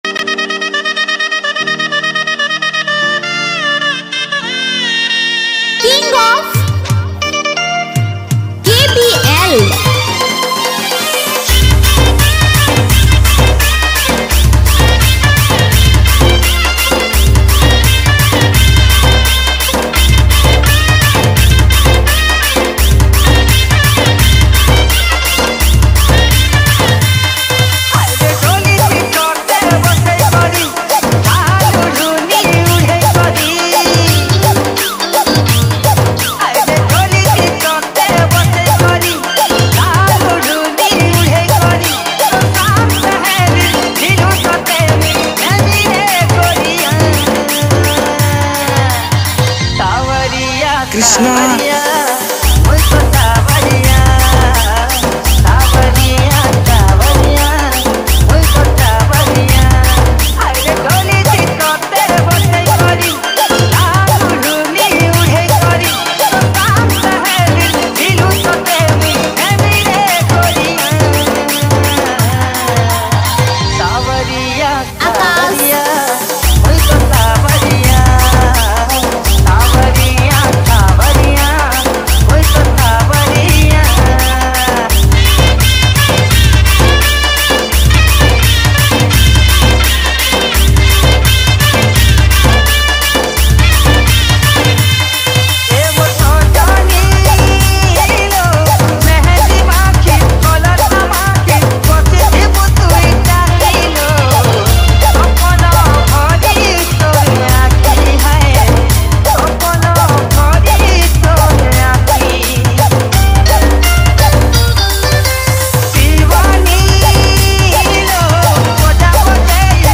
Category:  Old Sambalpuri Dj Song